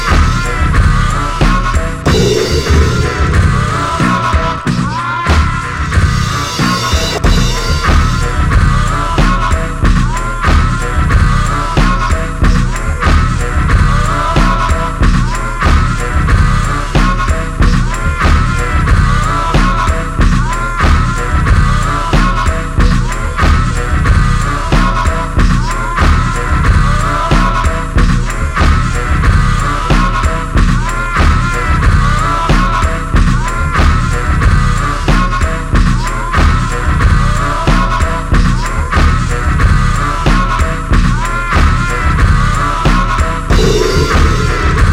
とありましたが、確かにそんなシチュエーションがバッチリハマるドラッギーな強力ウェポン！